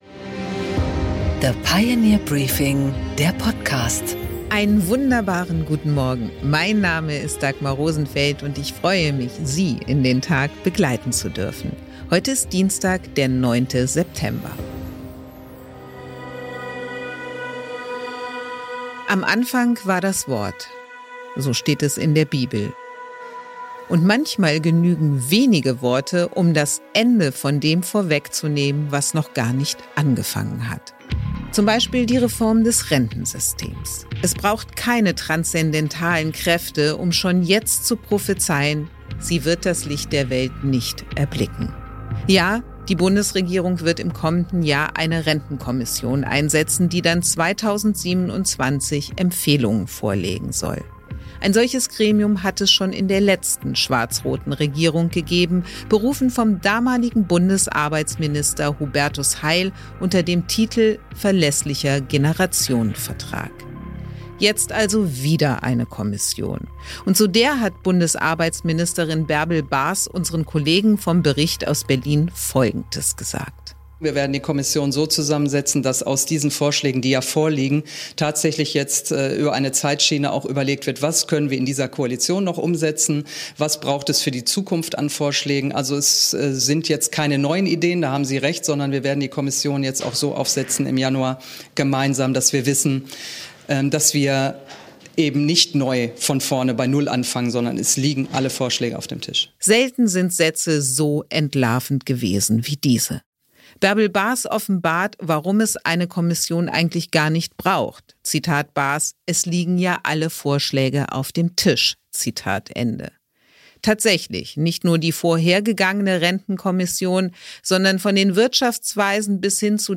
Dagmar Rosenfeld präsentiert das Pioneer Briefing
Wachstum, Wohlstand und Bienenpower – das erwartet Sie in der heutigen Ausgabe des Pioneer-Briefing-Podcasts, präsentiert von Dagmar Rosenfeld.
Im Gespräch: Prof. Clemens Fuest , Ökonom und Präsident des ifo Instituts, erklärt im Gespräch mit Dagmar Rosenfeld seine Sicht auf die Schuldenbremse, auf nachhaltiges Wachstum und auf die Herausforderungen für Deutschlands Wirtschaft.